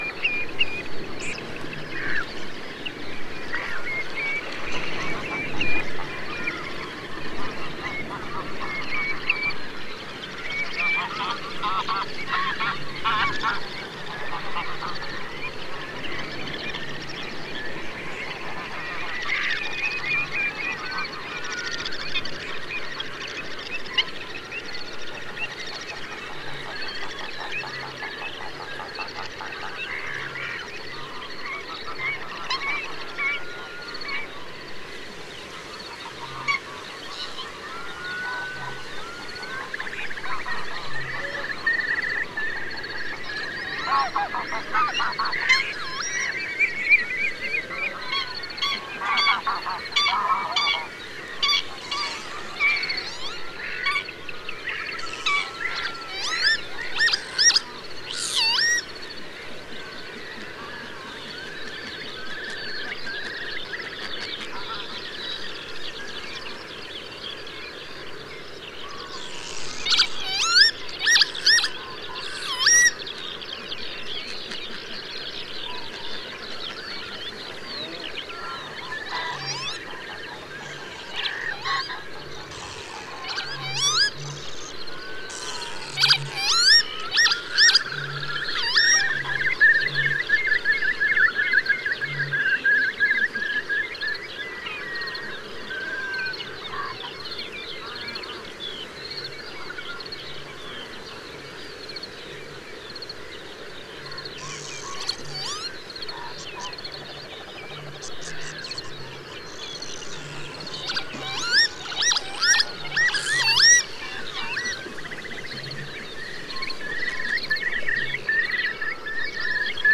100524, Northern Lapwing Vanellus vanellus, excitement call, Neusiedler See, Austria
lapwing_curlew.mp3